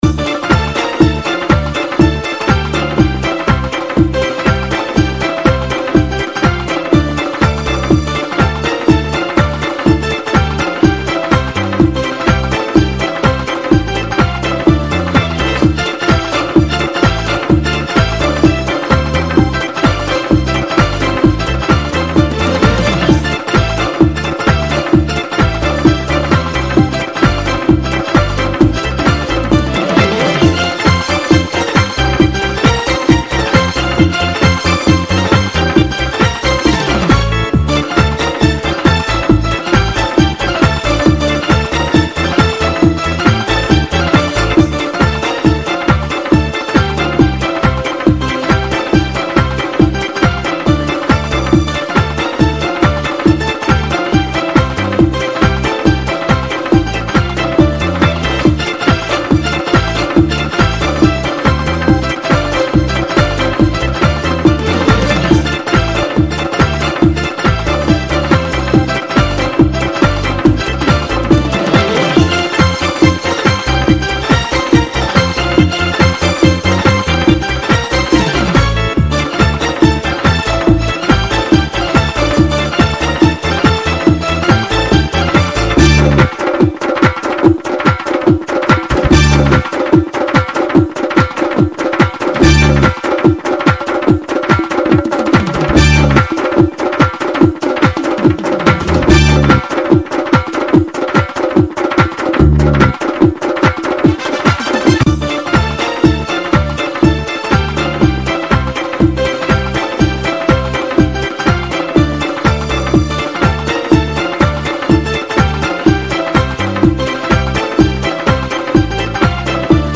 Главная » Файлы » ШАНСОН » лезгинки лезгинка грузинская